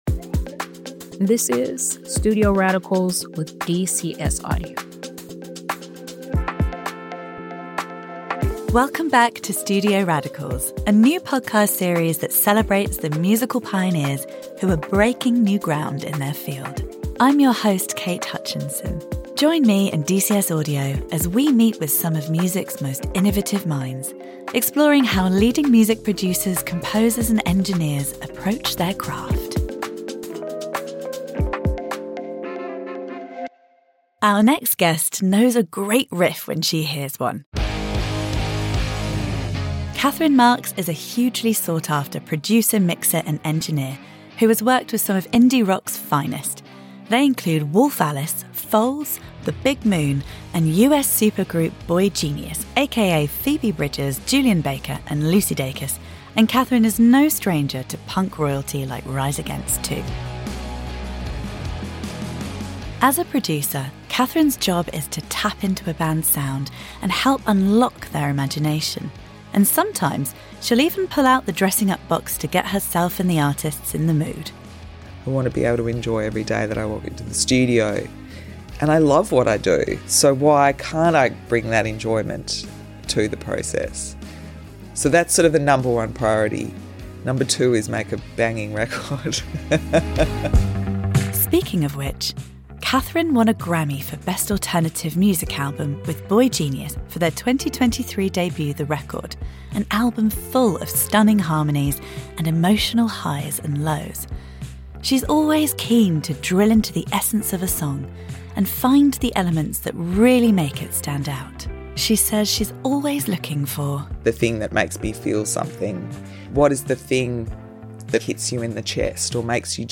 from her laidback space at Eastcote Studios in west London